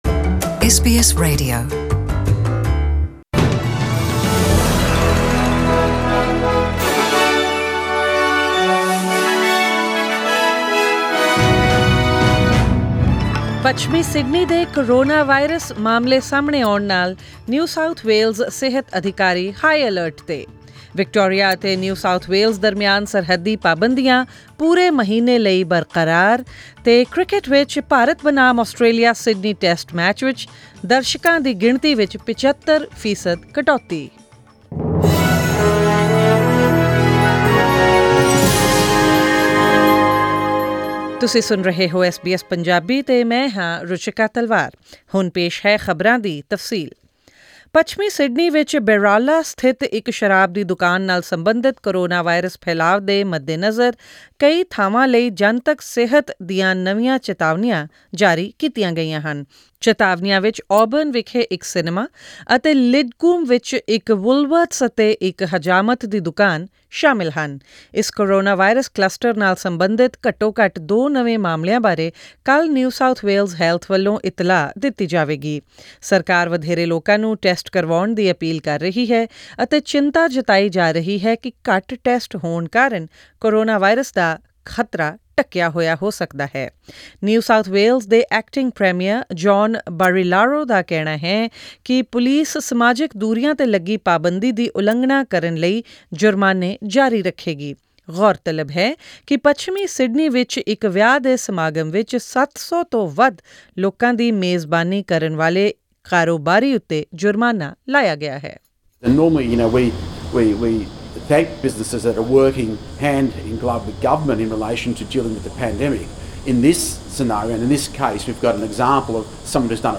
Attendance at the third Australia vs India Test at SCG on January 7 will be capped at 25 per cent capacity amid concerns around Sydney's two coronavirus clusters. Tune into tonight's bulletin for this and more national and international news stories, sports, currency exchange rates and the weather forecast for tomorrow.